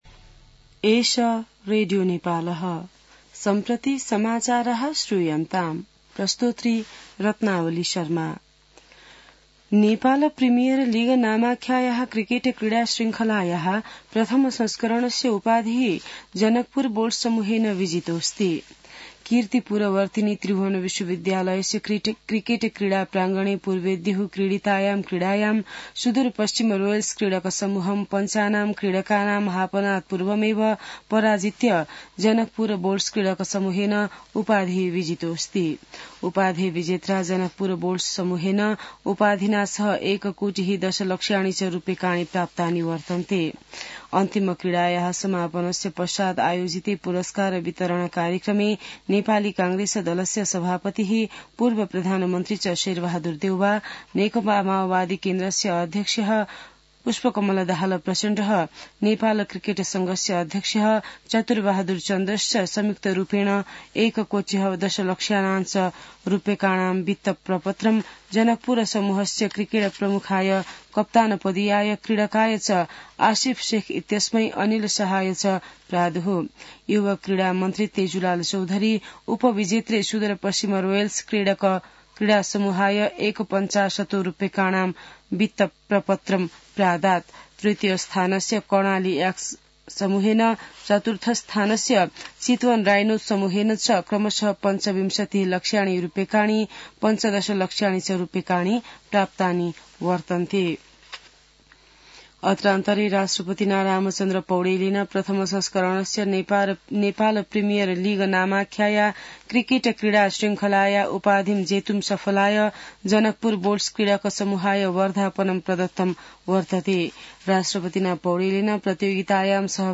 संस्कृत समाचार : ८ पुष , २०८१